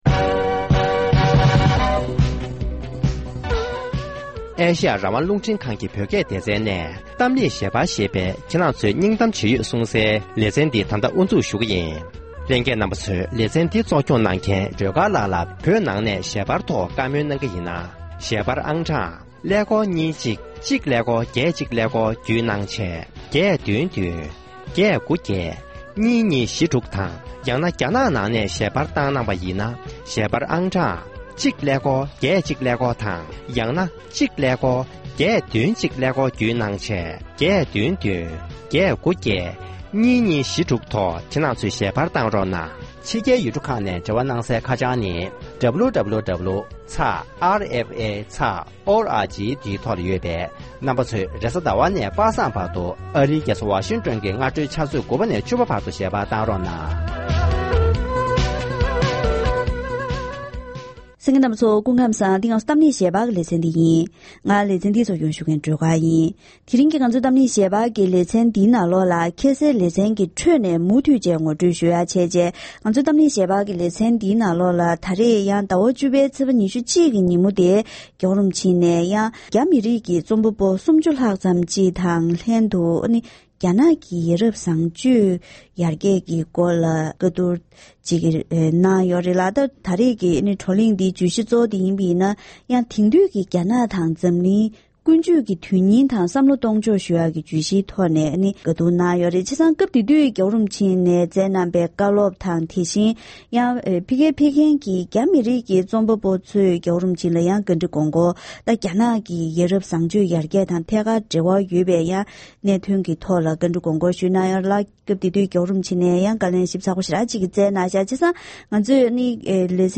དེ་རིང་གི་གཏམ་གླེང་ཞལ་པར་ལེ་ཚན་ནང་སྤྱི་ནོར་༸གོང་ས་༸སྐྱབས་མགོན་ཆེན་པོ་མཆོག་ནས་ཉེ་ཆར་རྒྱ་མི་རིགས་ཀྱི་རྩོམ་པ་པོ་ཁག་ཅིག་ལྷན་རྒྱ་ནག་ནང་གི་ཡ་རབས་བཟང་སྤྱོད་ཡར་རྒྱས་གཏོང་ཕྱོགས་ཐད་བགྲོ་གླེང་ཞིག་གནང་ཡོད་པས། བགྲོ་གླེང་འདིའི་ཐོག་༸གོང་ས་མཆོག་གི་བཀའ་སློབ་དང་རྒྱ་མི་རིགས་ཀྱི་ཤེས་ཡོན་ཅན་ནས་བཀའ་འདྲི་གང་ཞུས་སྐོར་ཞིབ་ཕྲ་ངོ་སྤྲོད་ཞུས་པའི་ལེ་ཚན་སྨད་ཆ་འདི་གསན་རོགས་གནང་།།